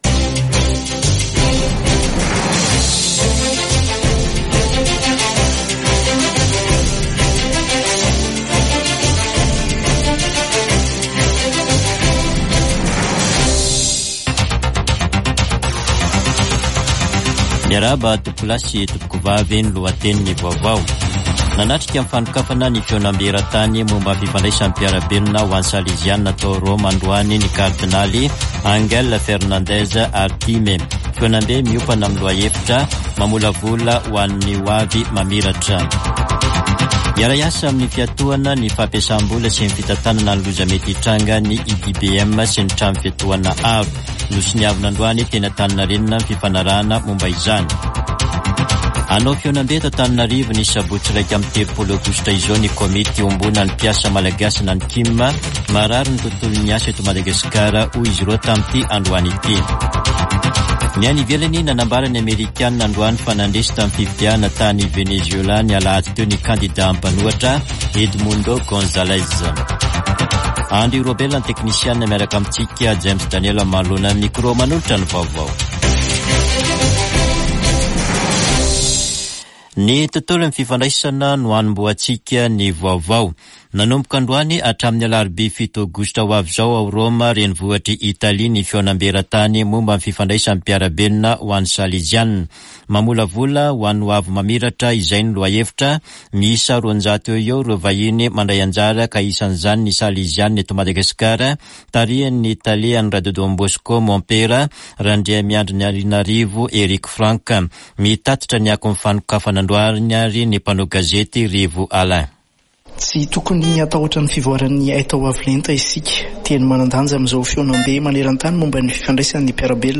[Vaovao hariva] Zoma 2 aogositra 2024